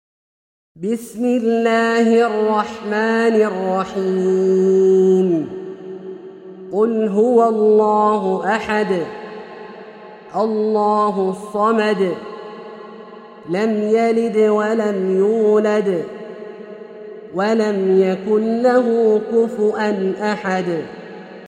سورة الإخلاص - برواية الدوري عن أبي عمرو البصري > مصحف برواية الدوري عن أبي عمرو البصري > المصحف - تلاوات عبدالله الجهني